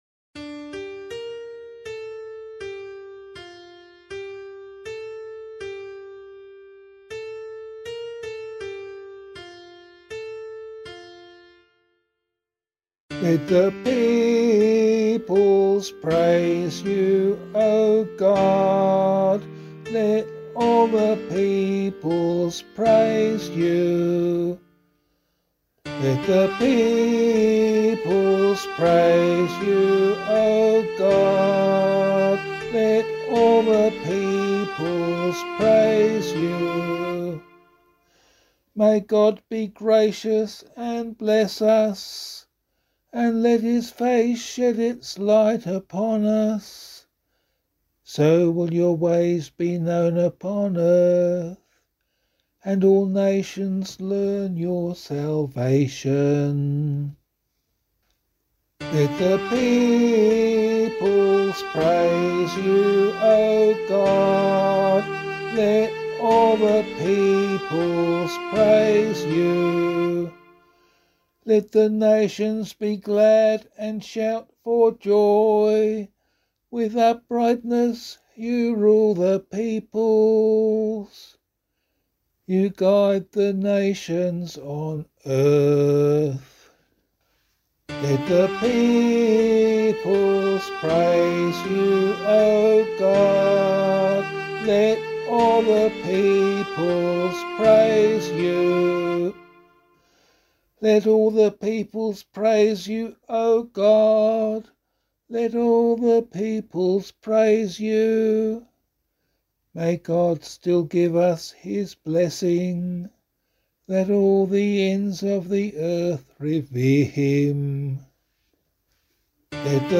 028 Easter 6 Psalm C [APC - LiturgyShare + Meinrad 3] - vocal.mp3